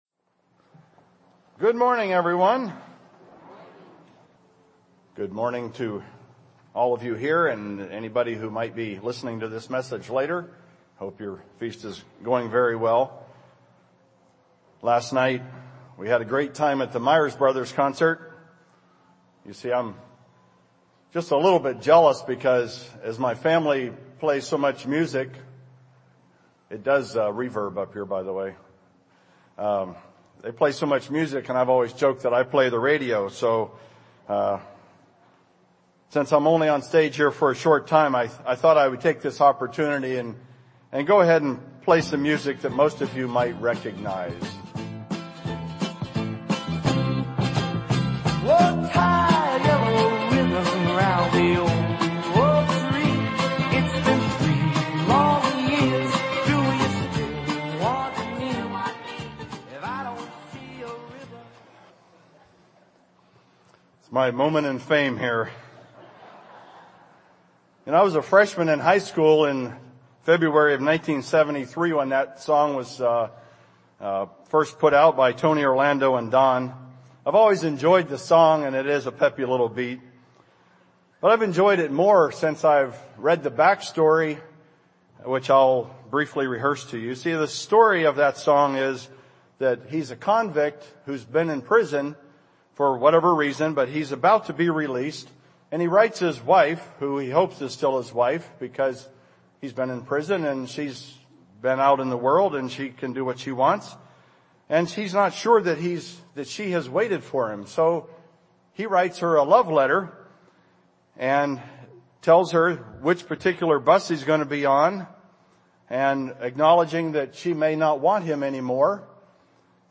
This sermon was given at the Wisconsin Dells, Wisconsin 2015 Feast site.